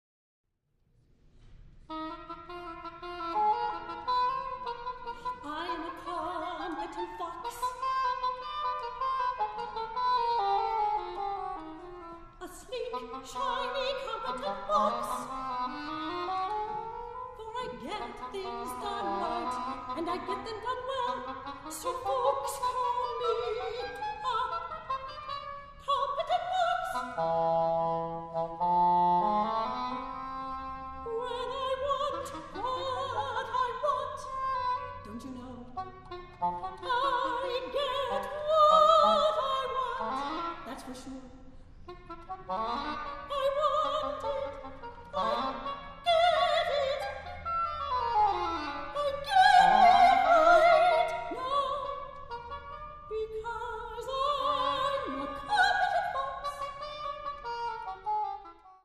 For soprano and English horn.